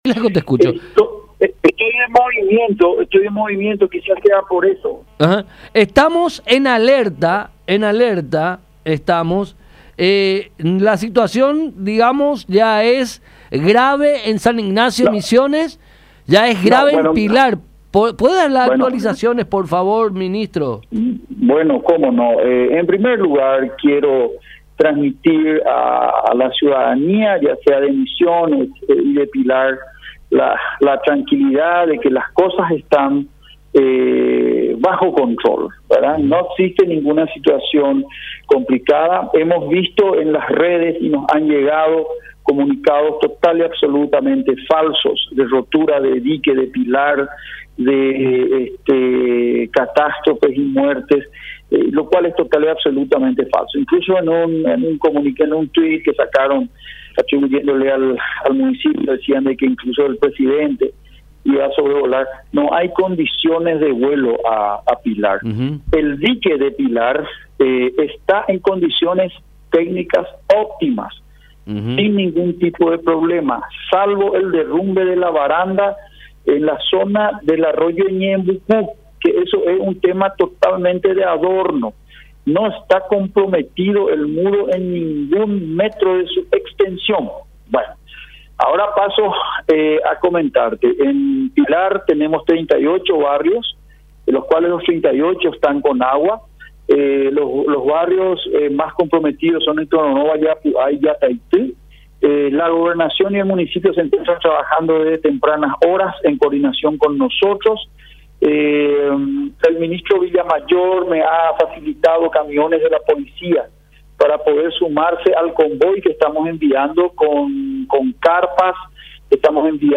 En conversación con La Unión, el ministro de Secretaría Nacional de Emergencia, Joaquín Roa, desmintió que el muro de Pilar se haya derrumbado y pidió tranquilidad para la población de Misiones.